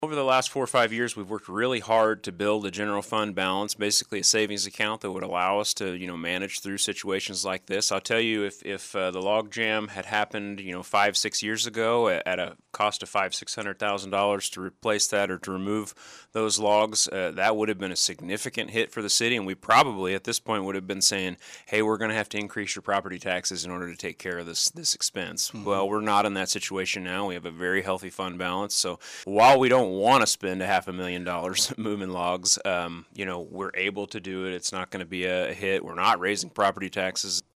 Interim City Manager Jacob Wood joined in on the KSAL Morning News Extra with a look at issues concerning Salina during his monthly scheduled visit to the radio station.